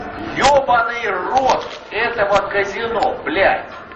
мужской голос
голосовые